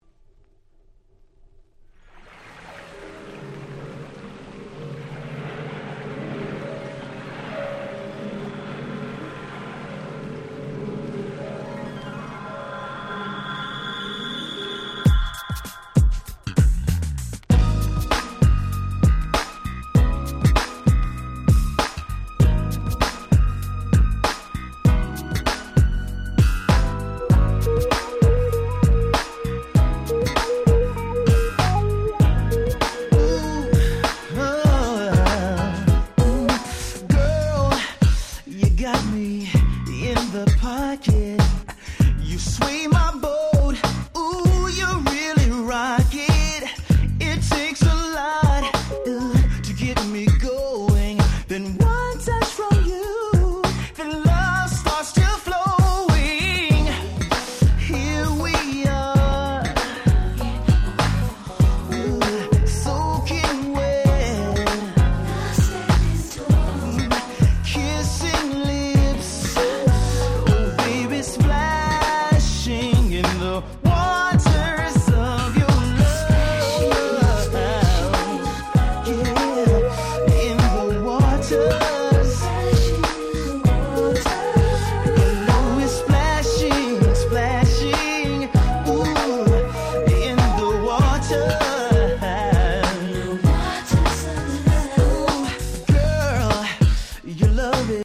02' Nice Soul/R&B !!
詳細不明ですが、Oallandの黒人のおじさん5人組のバンド。
よりどりみどりな甘い曲調の4曲は派手さは無い物の年季を感じさせる良曲揃い！
NJSっぽくハネてる曲もあったりでなかなかどうして。